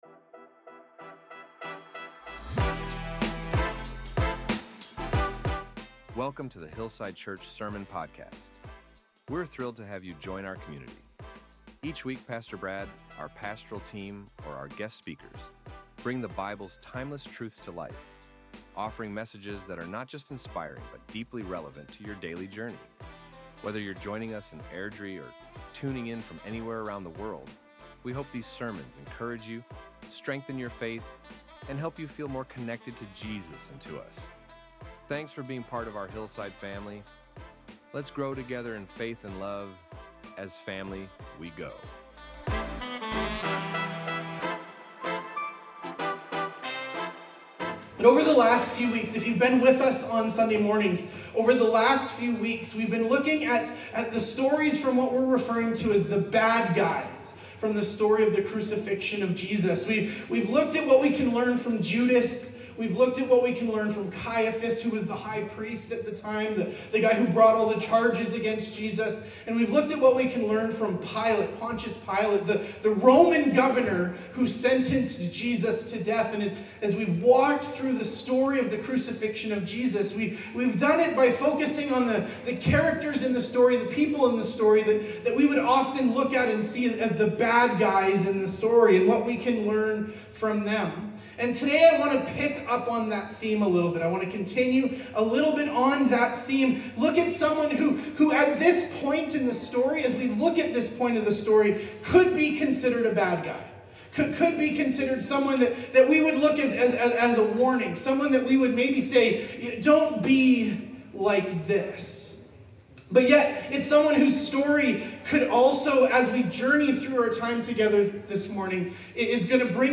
Our Sermons | HILLSIDE CHURCH